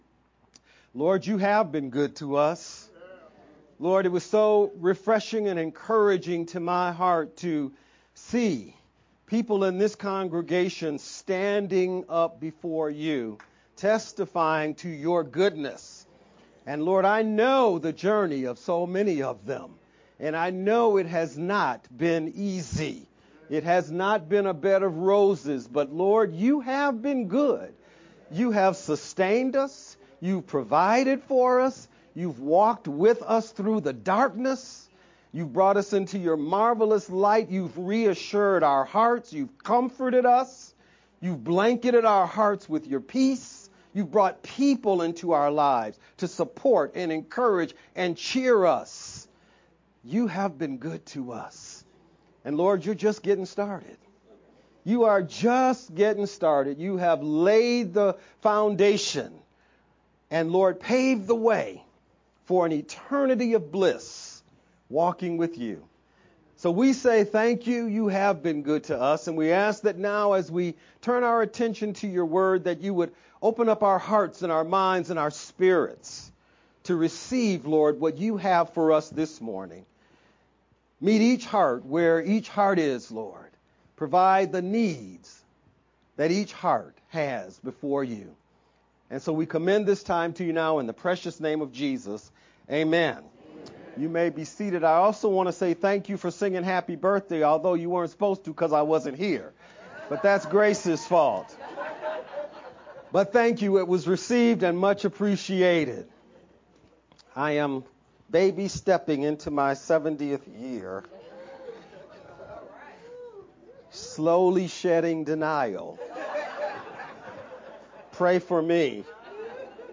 July-6th-Sermon-only-edited_Converted-CD.mp3